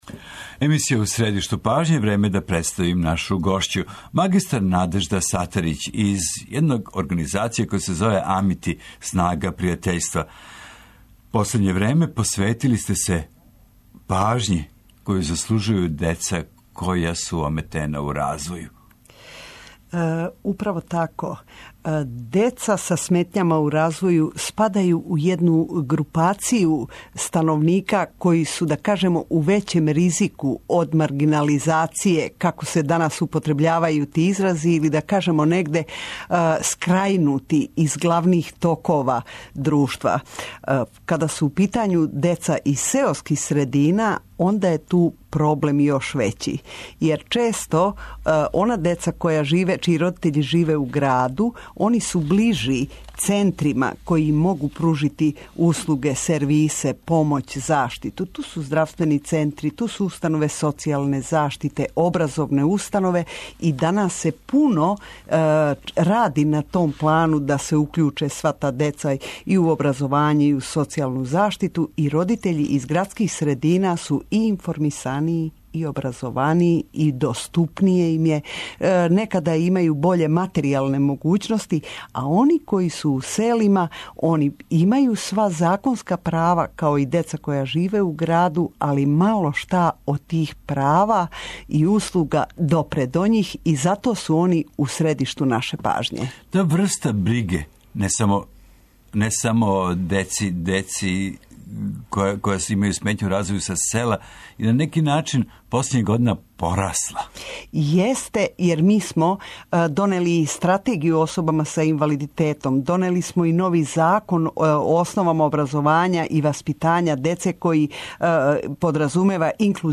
интервју